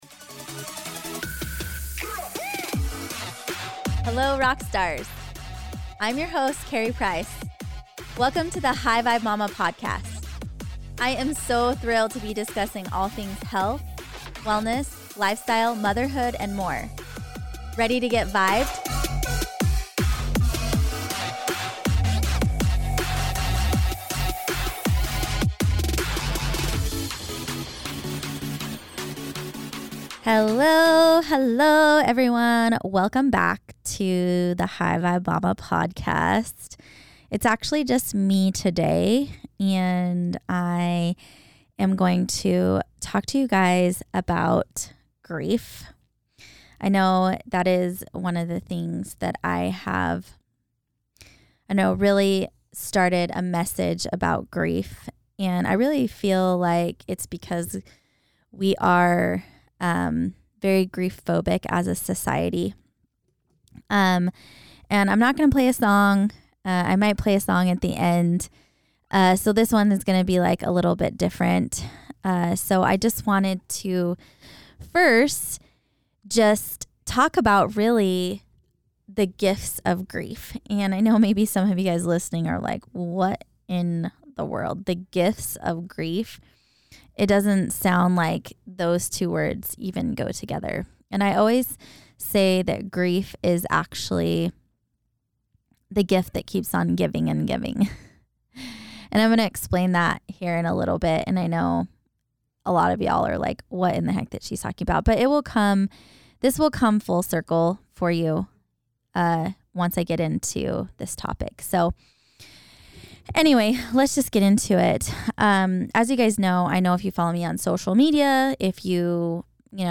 Solo, sharing my heart with you and this grief journey I have been on for the past (almost) 3 years.